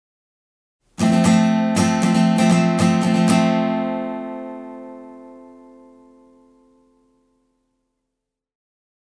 ６弦と５弦、そして１弦をミュートして
簡易化したＦコード
簡易化したほうがちょっと低音成分が少ないですが